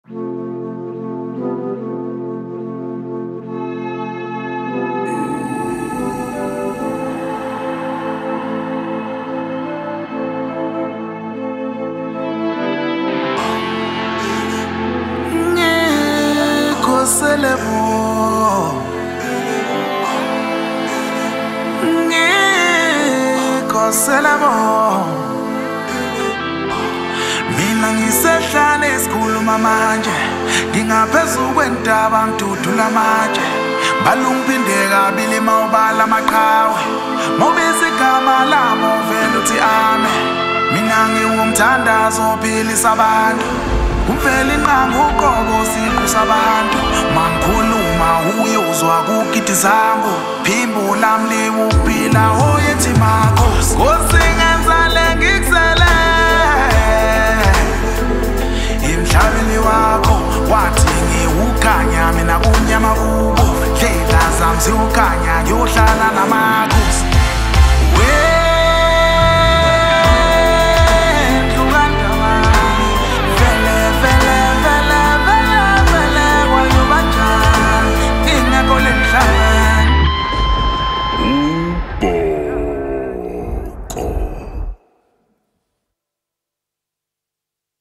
The song is very melodious and engaging